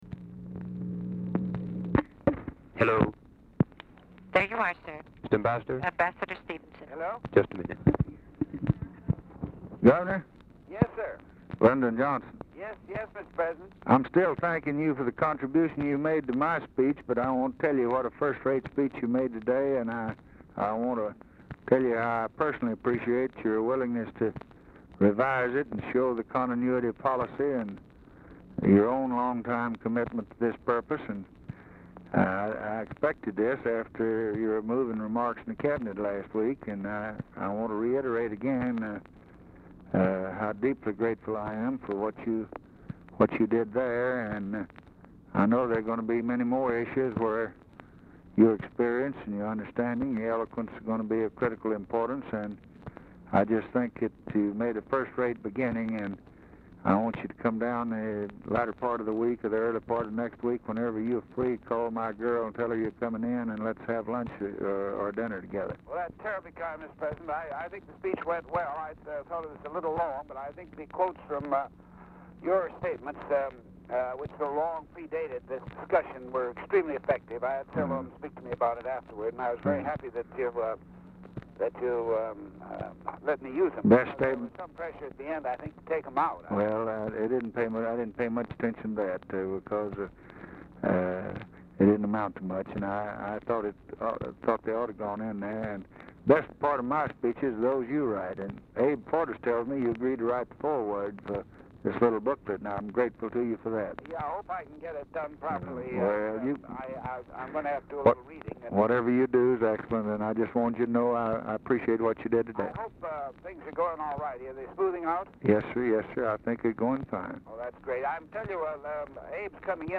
Title Telephone conversation # 243, sound recording, LBJ and ADLAI STEVENSON, 12/2/1963, 5:40PM Archivist General Note "#5"; "(TRANSCRIBED)"; PREVIOUSLY OPENED 4/1994; BILL MOYERS? ANSWERS CALL, ASKS STEVENSON TO HOLD FOR LBJ
Format Dictation belt
Location Of Speaker 1 Oval Office or unknown location